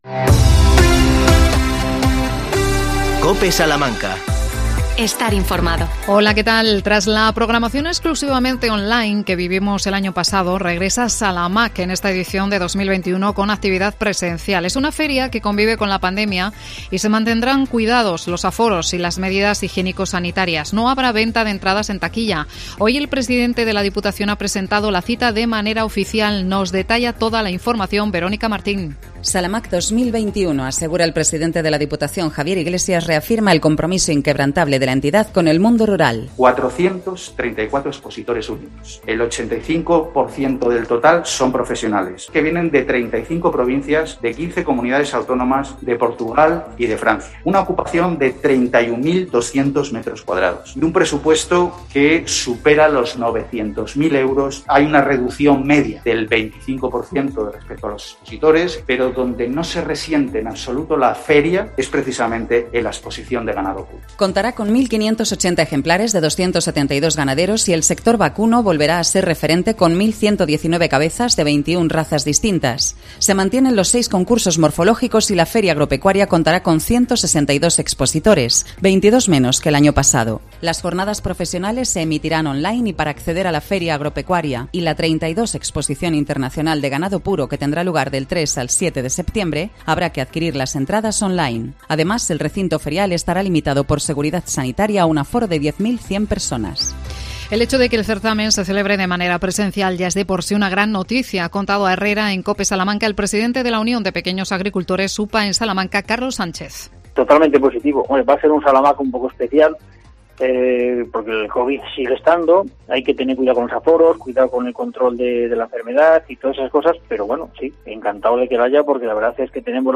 27 08 21 MEDIODIA INFORMATIVO COPE SALAMANCA